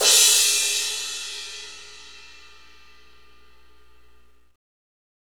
Index of /90_sSampleCDs/Northstar - Drumscapes Roland/CYM_Cymbals 3/CYM_H_H Cymbalsx